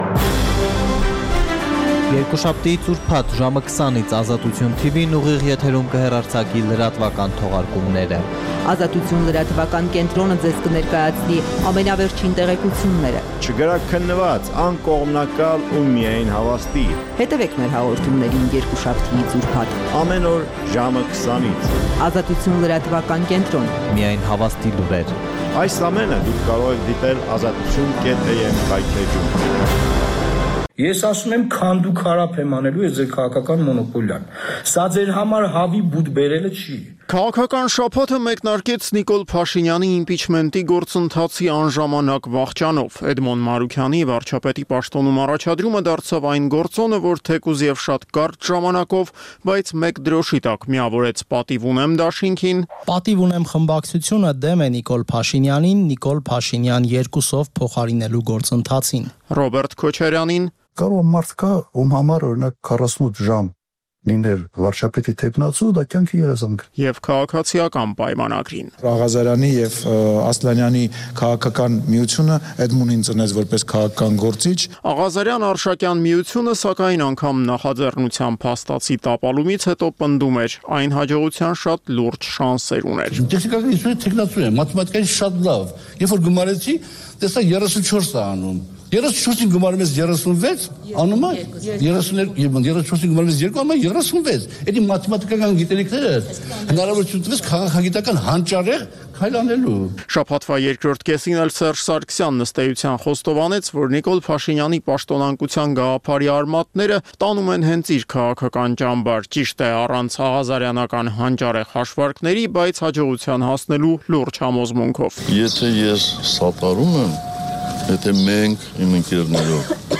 Listen Live - Ուղիղ հեռարձակում - Ազատություն ռ/կ